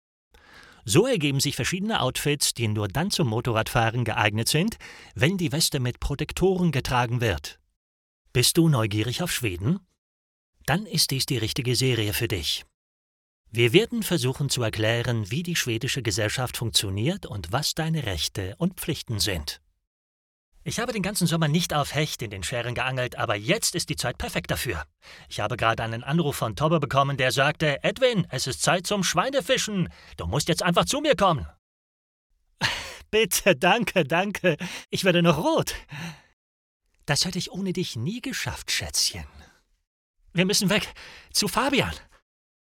Male
Assured, Character, Cheeky, Confident, Cool, Engaging, Friendly, Natural, Smooth, Witty, Versatile, Authoritative, Corporate, Warm
Microphone: Neumann U87